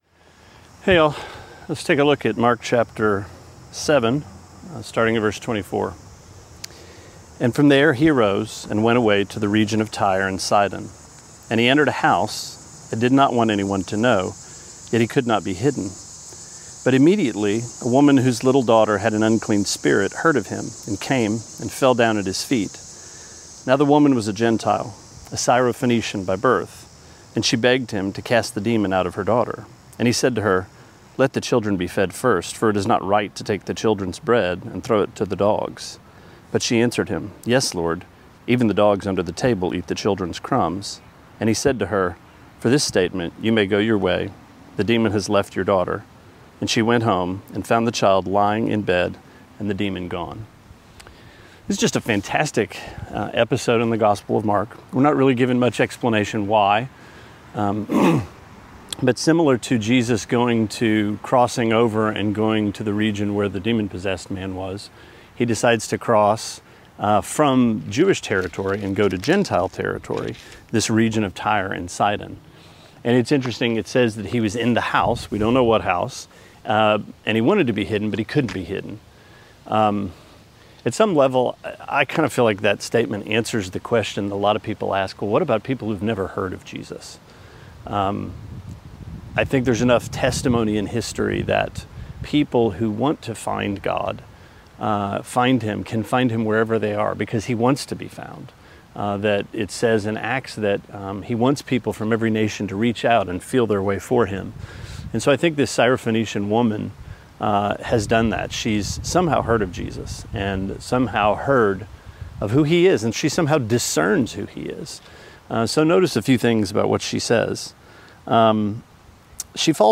Sermonette 7/10: Mark 7:24-30: Dog